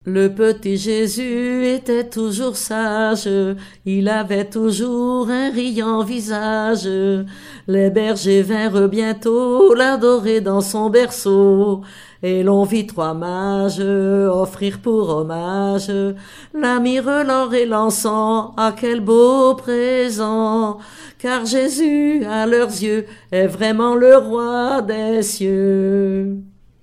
collectif de chanteuses de chansons traditionnelles
Pièce musicale inédite